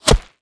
bullet_impact_dirt_03.wav